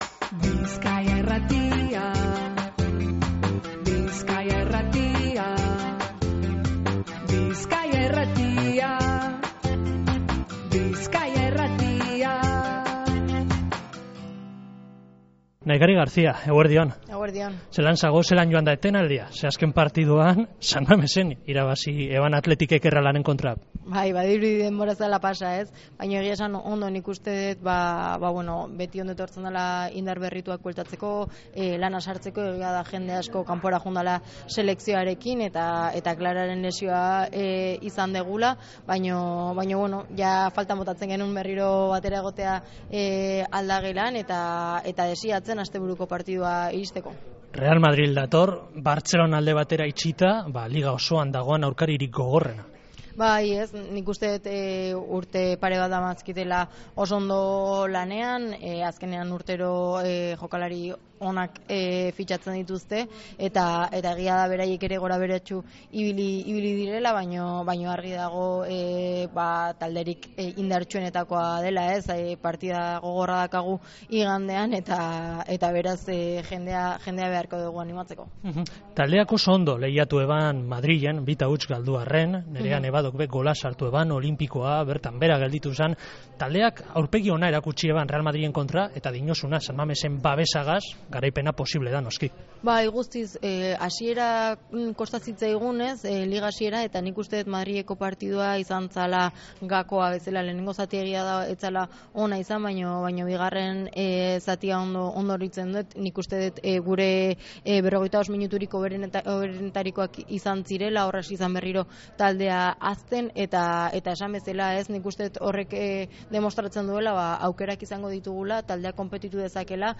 Domekako partiduaren atarian, Nahikari Garcia Athleticeko aurrelari gipuzkoarragaz egin dogu berba Jokoan irratsaioan.